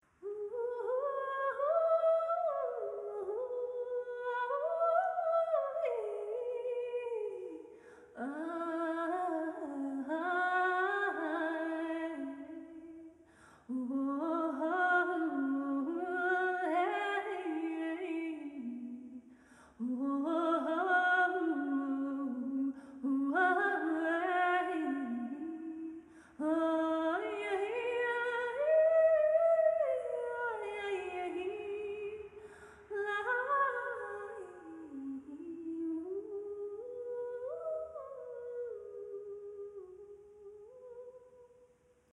Liebevoll singe ich es zurück in Dein Bewusstsein.
Mitschnitte zum Lauschen & Kennenlernen meiner Musik. Improvisationen mit dem Ensemble „Musik der Religionen“ Berlin.